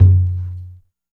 80 TUNED TOM.wav